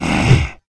spawners_mobs_mummy_attack.ogg